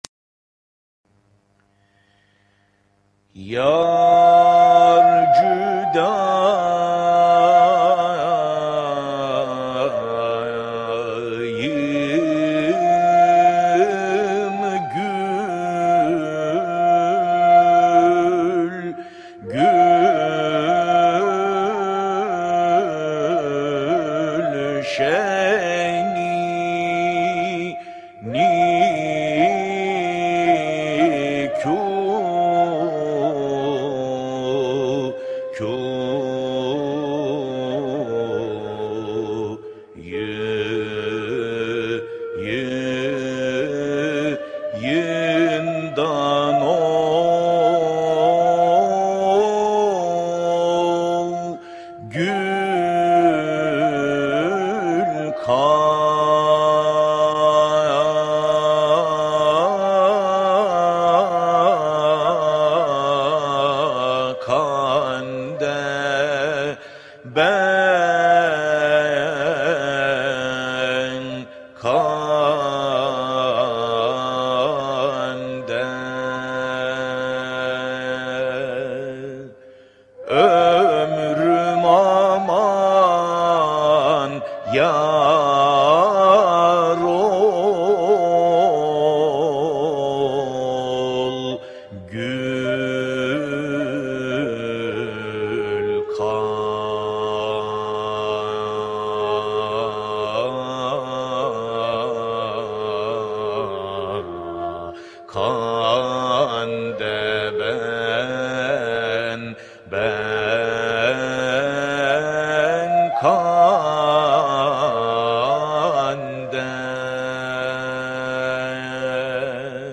Makam: Sultani Irak Form: Beste Usûl: Nim Devir